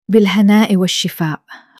بِالهَناءِ وَالشِفاء (Bil-hanā’i wa ash-shifā’) – رایج‌ترین معادل نوش جان به عربی